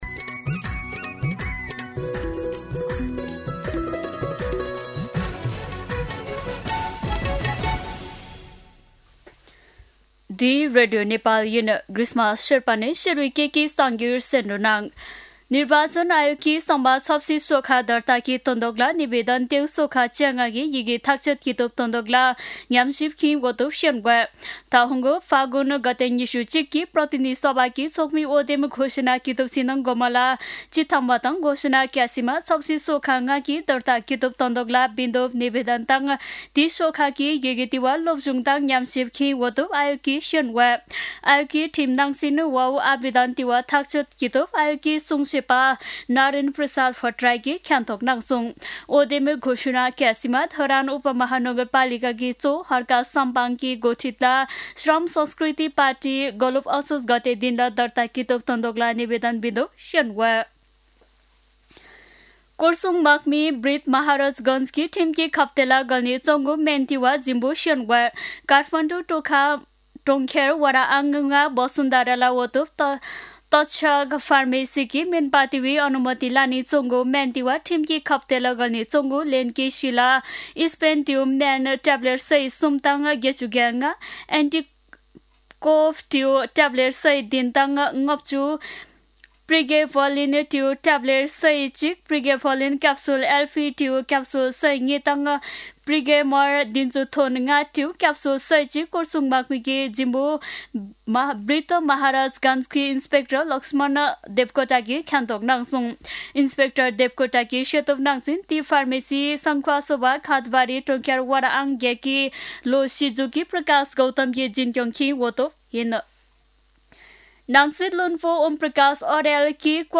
शेर्पा भाषाको समाचार : ७ कार्तिक , २०८२
sherpa-News-3.mp3